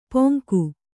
♪ poŋku